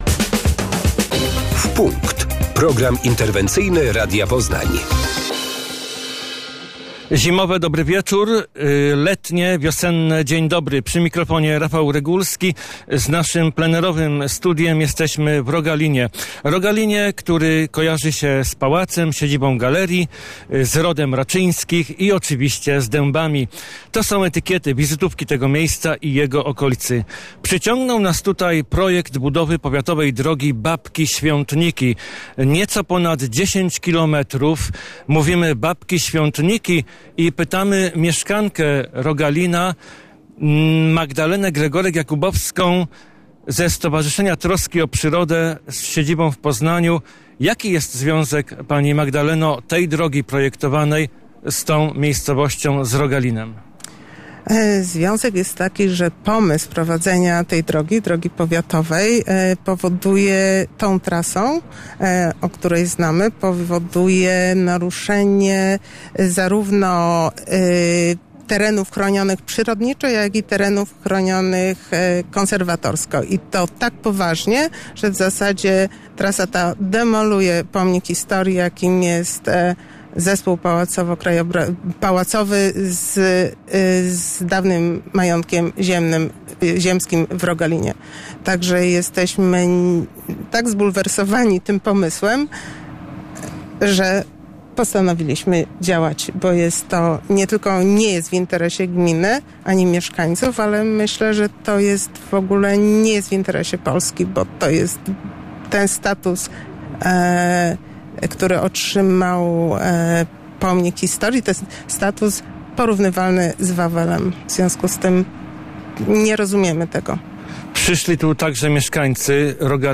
Budowa drogi powiatowej Babki-Świątniki w powiecie poznańskim to inwestycja, którą zajęliśmy się w najnowszym programie interwencyjnym "W punkt". Plenerowe studio Radia Poznań stanęło w Rogalinie, obok siedziby spółki Majątek Rogalin.